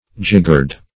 Meaning of jiggered. jiggered synonyms, pronunciation, spelling and more from Free Dictionary.